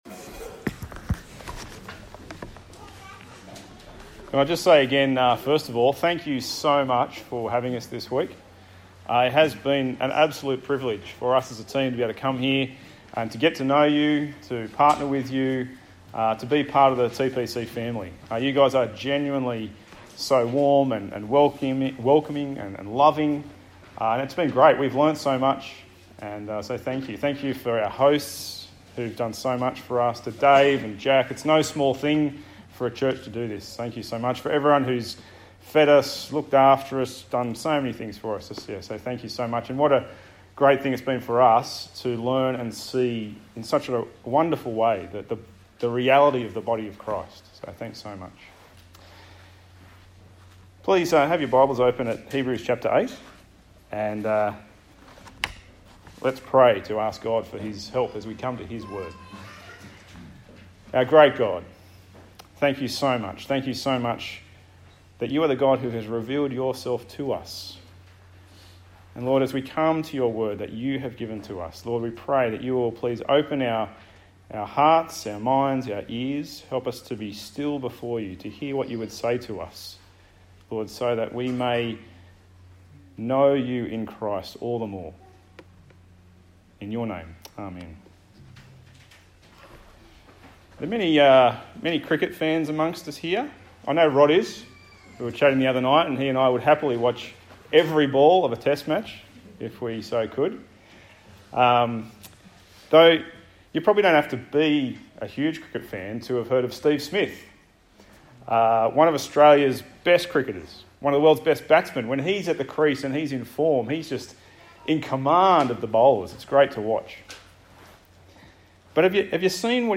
Passage: Hebrews 8 Service Type: Sunday Morning